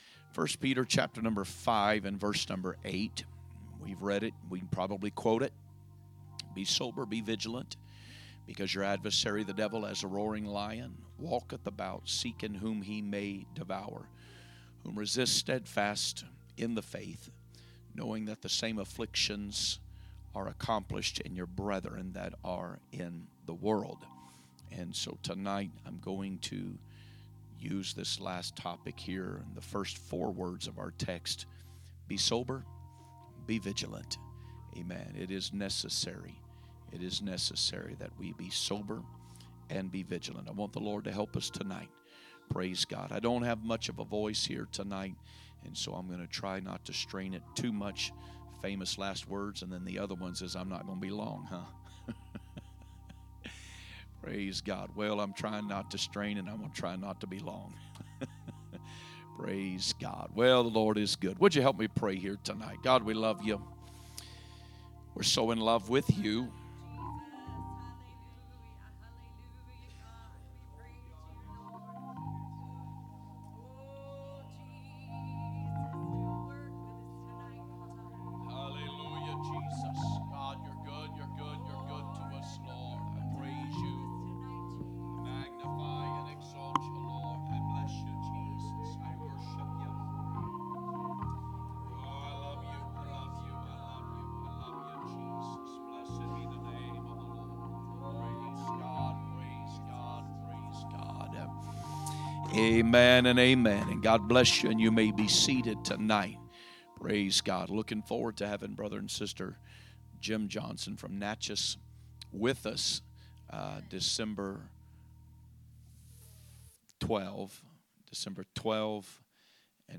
A message from the series "2025 Preaching."